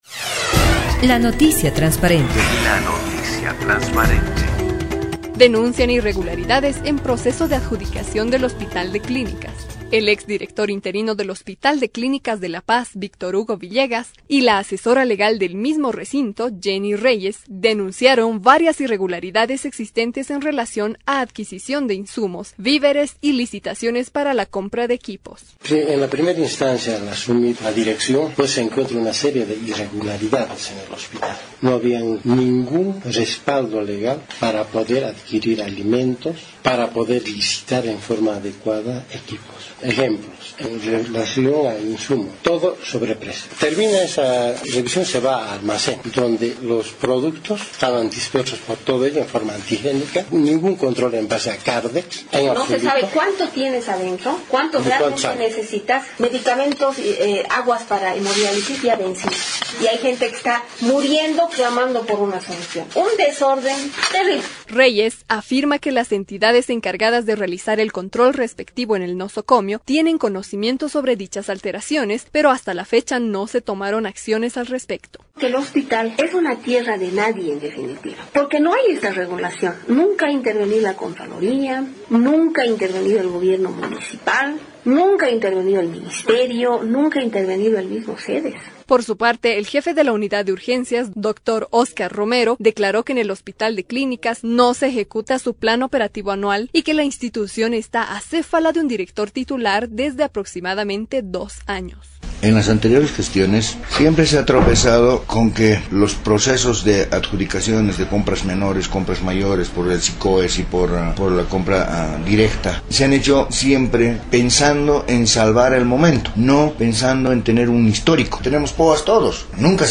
noticia_transparente_irregularidades_hospital_clinicas.mp3